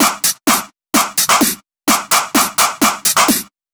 VMH1 Minimal Beats 16.wav